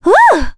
Ophelia-vox-Happy4.wav